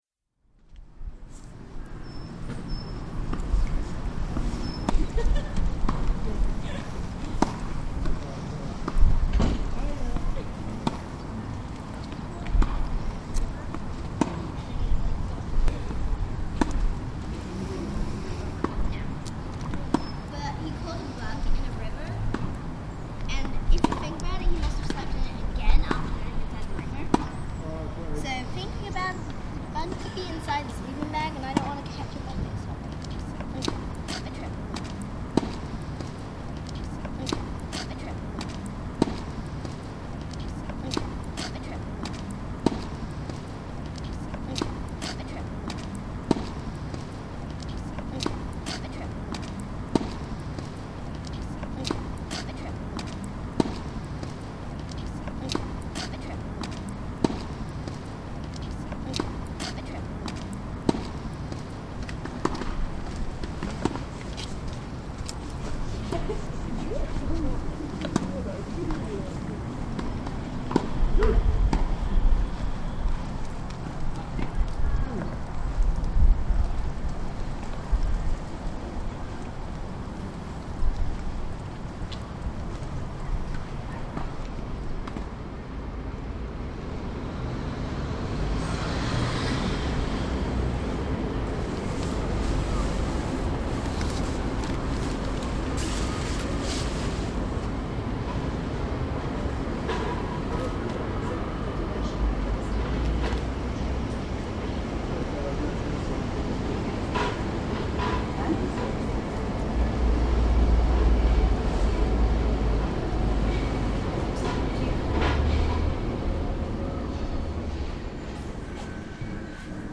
sound composition and installation
a series of walks were conducted during a one month period prior to the exhibition, collecting field recordings and photographs.
the patterns and rhythms unique to the visual and sonic landscape of the area were documented. the work culminated in a bespoke soundscape to complement the interior of the cinema's foyer for guests to enjoy whilst passing through the space.